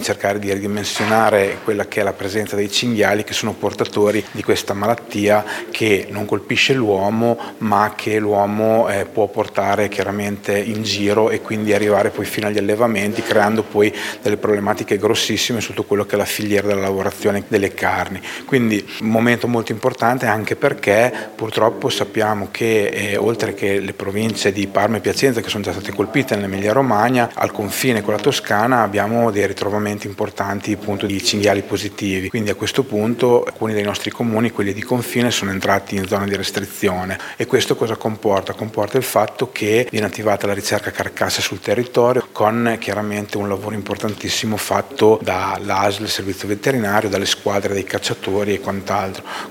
Il presidente della Provincia Fabio Braglia: